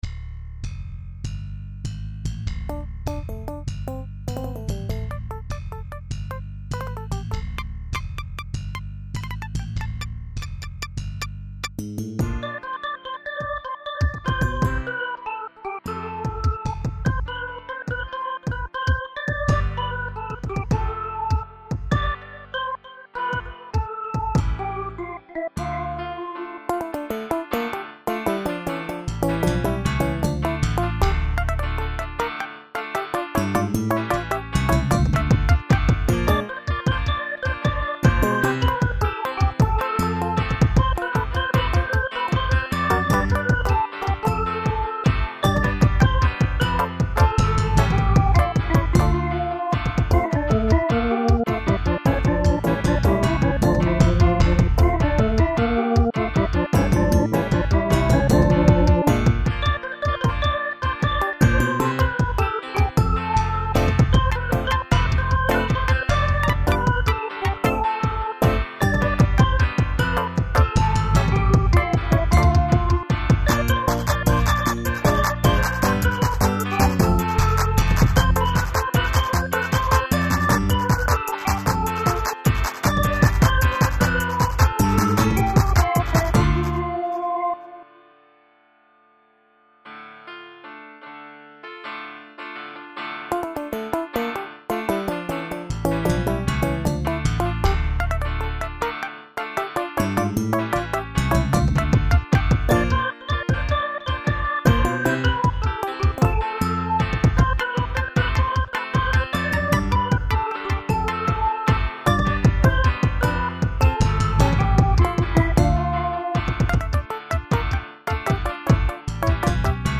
folclore_1 progresivo_1 virtuales